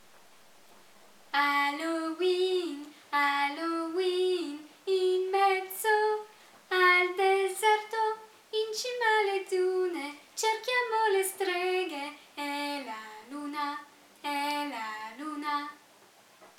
e Halloween in filastrocca:
♫ (Melodia di Fra’ Martino)